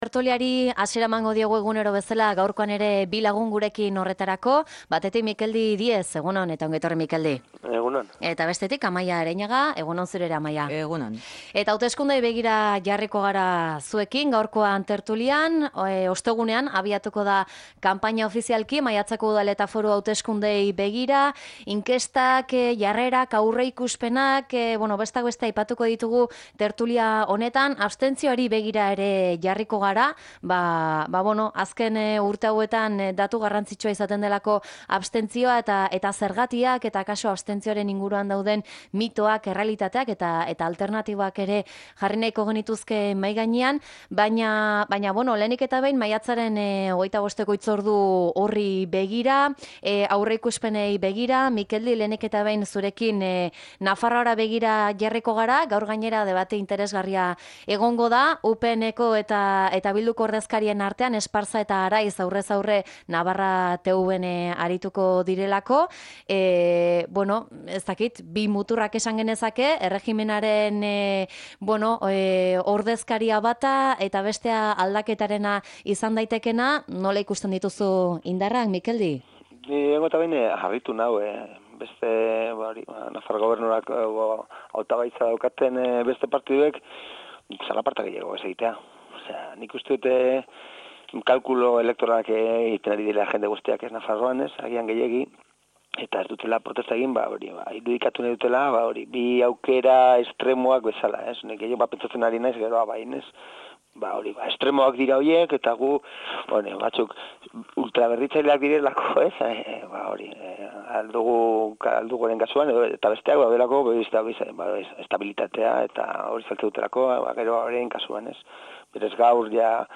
Tertulia: hauteskundeak eta abstentzioa